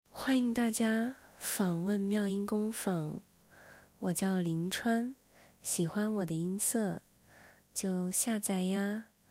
林川 少年/幼态/正太音少年感 RVC模型
幼态推理后
温御推理前